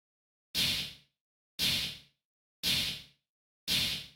MOO Beat - Mix 12.wav